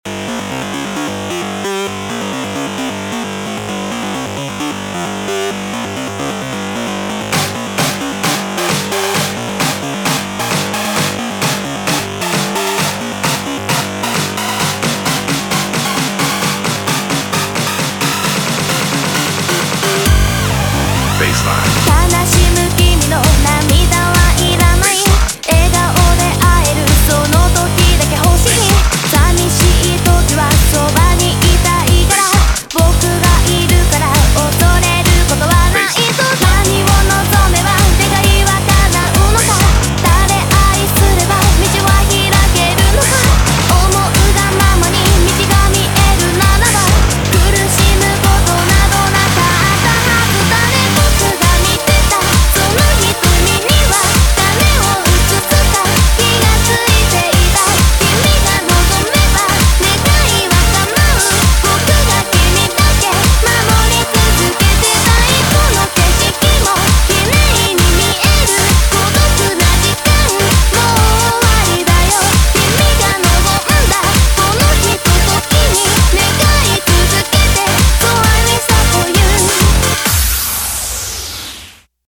BPM132
Audio QualityPerfect (High Quality)
Genre: ELECTRO HOUSE.